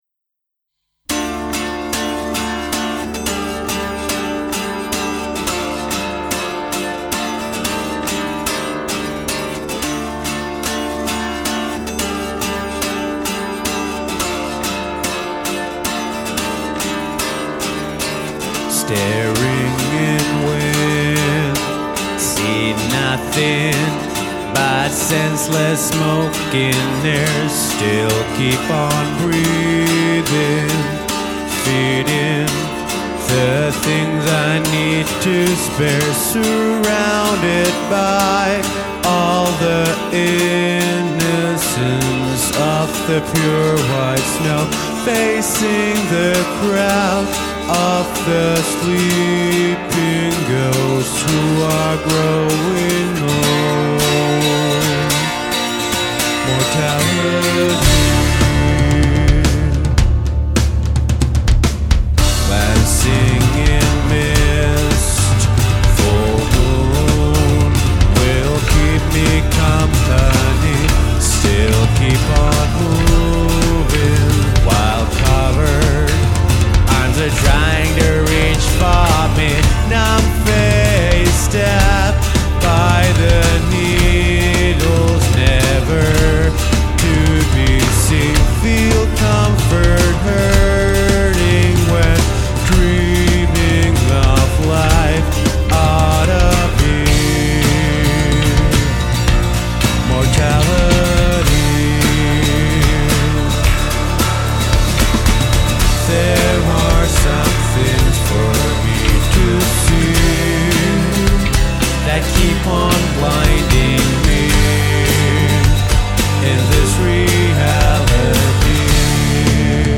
Recorded in OUBS Studios, Espoo in Dec 02nd - 06th of 2005